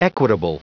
Prononciation du mot equitable en anglais (fichier audio)
Prononciation du mot : equitable